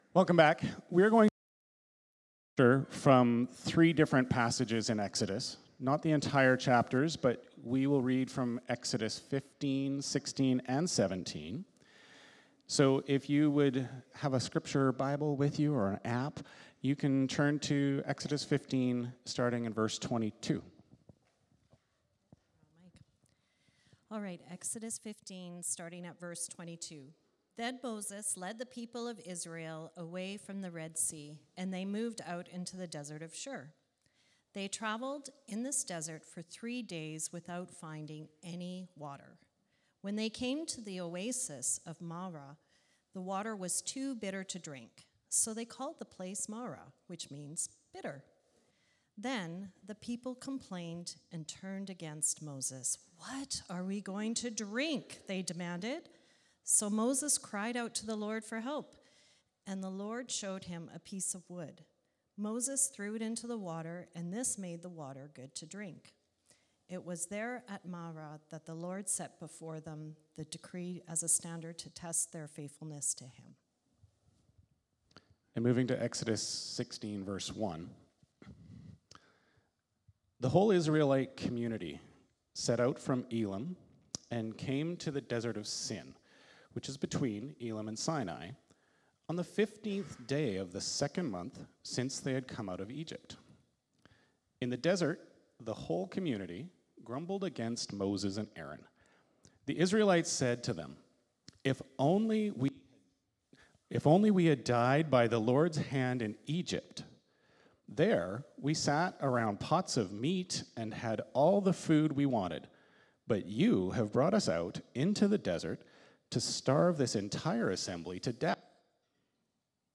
Sermon Series – Hillside Church